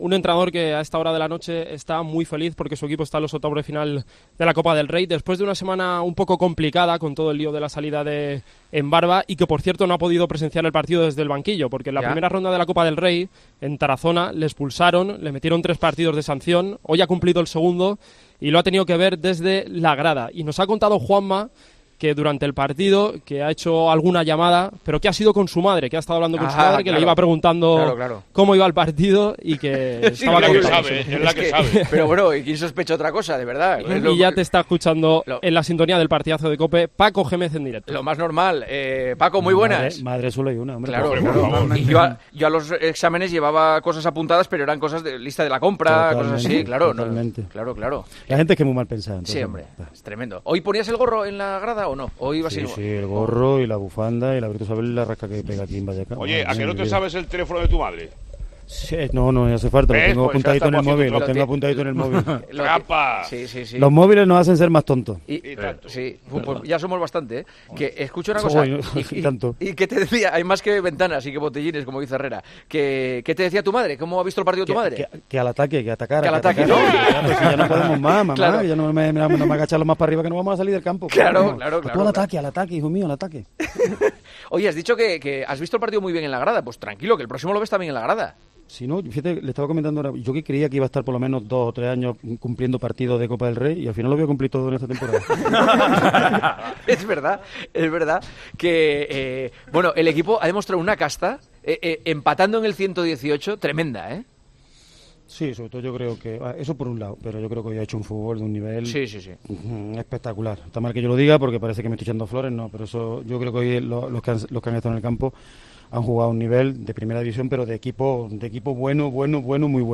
Paco Jémez atendió a Juanma Castaño en El Partidazo de COPE este jueves, después de que el Rayo Vallecano se clasificara para los octavos de final de la Copa del Rey tras eliminar al Real Betis en la tanda de penaltis.